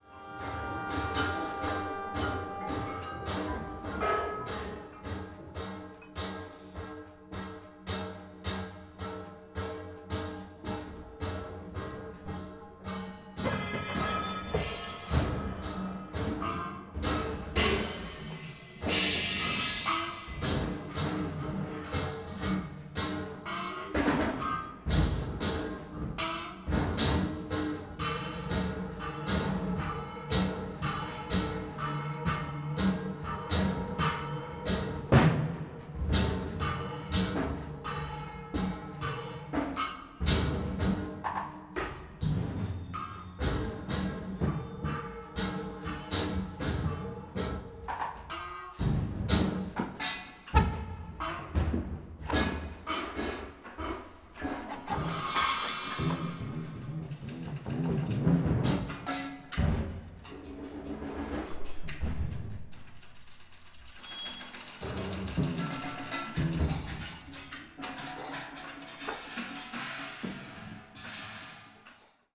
percussionist
Great honor to participate in the legendary course for new music in Darmstadt.
Excerpt from Autoschediasms (my solo), recorded by the local radio in Darmstadt:
solo-autoschediasms.wav